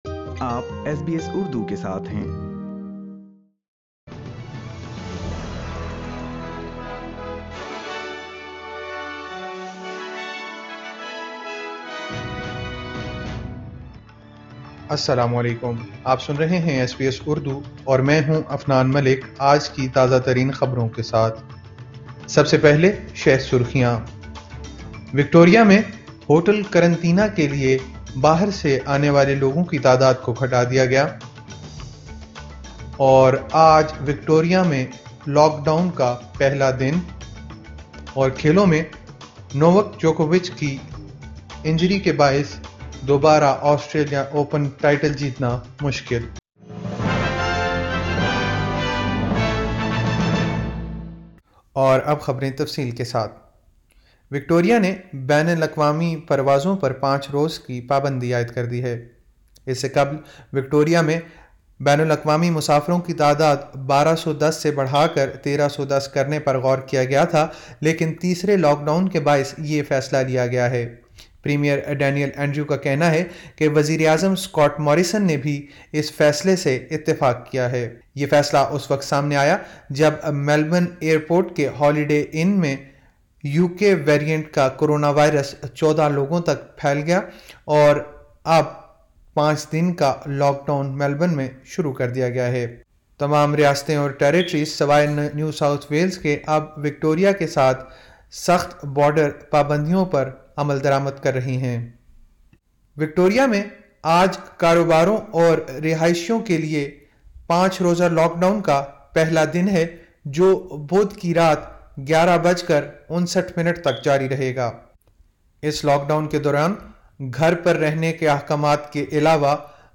ایس بی ایس اردو خبریں 13 فروری 2021